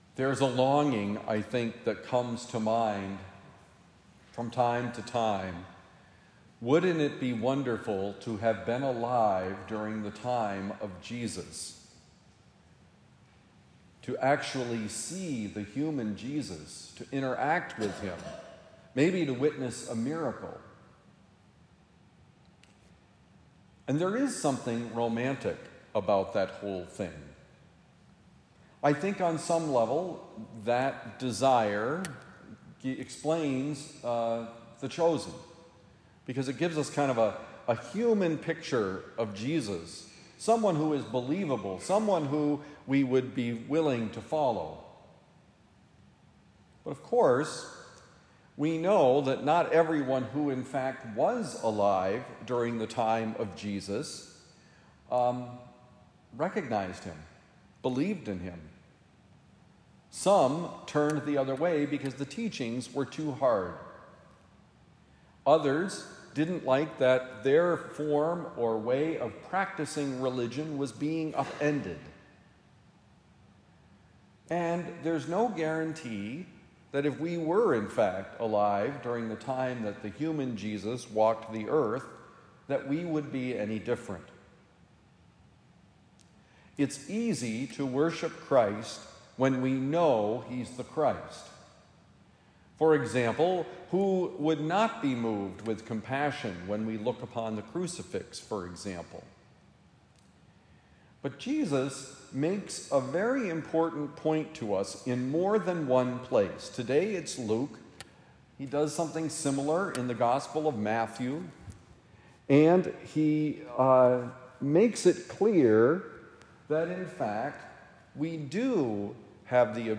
Can we be bothered? Homily for Sunday, July 13, 2025 – The Friar